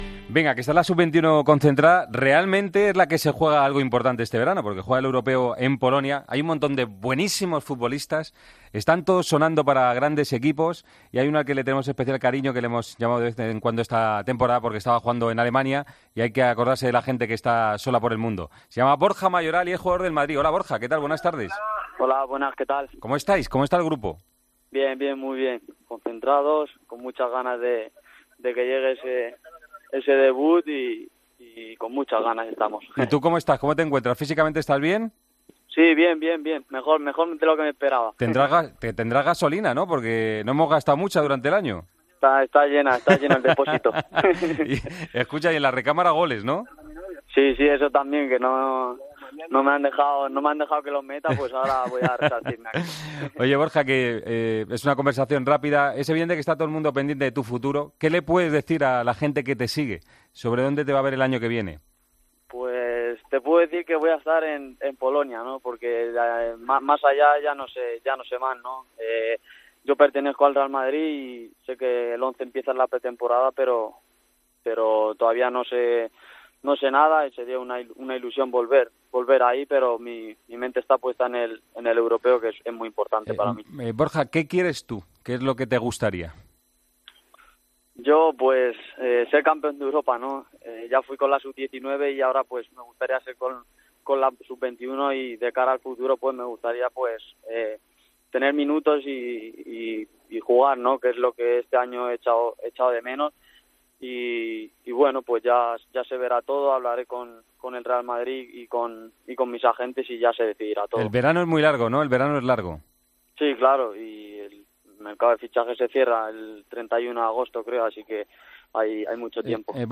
Entrevista en Deportes COPE